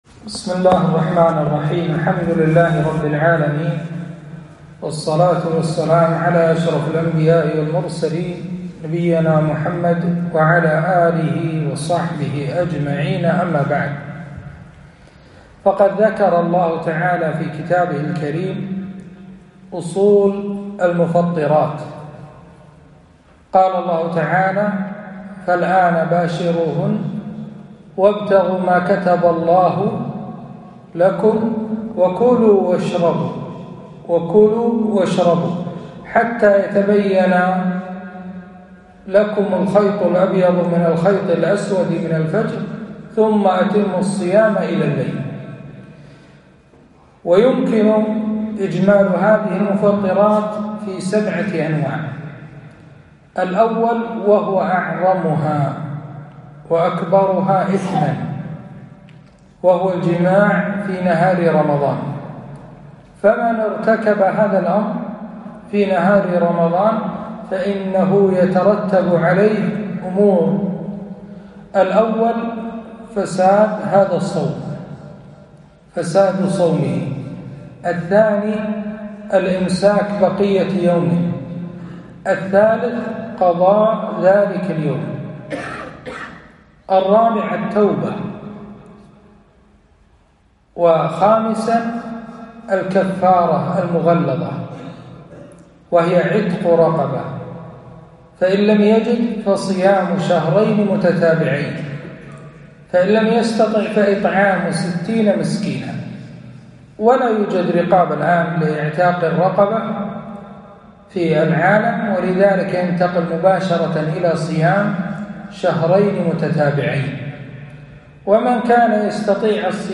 محاضرة - مفطرات الصيام